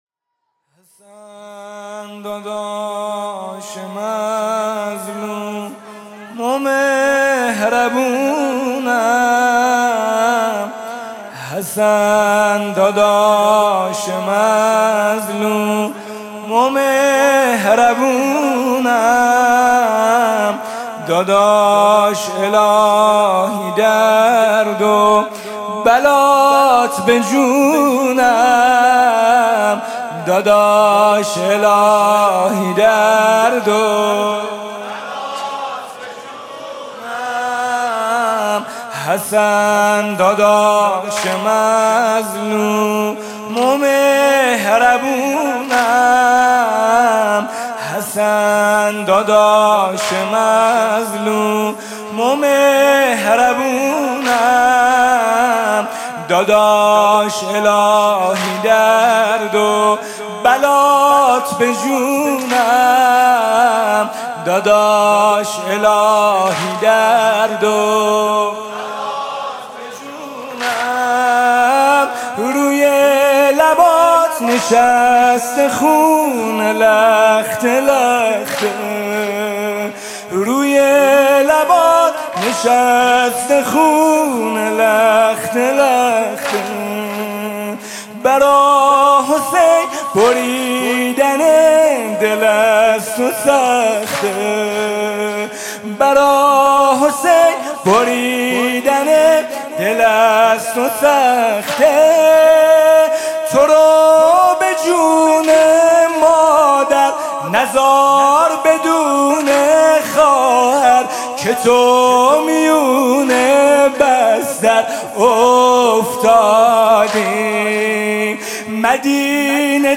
music-icon واحد: حسن داداش مظلوم و مهربونم